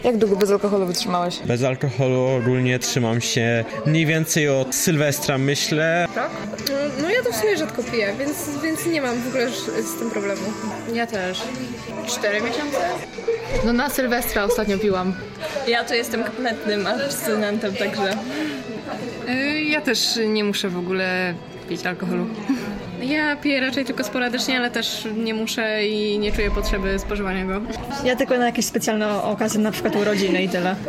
sonda-alkohoool.mp3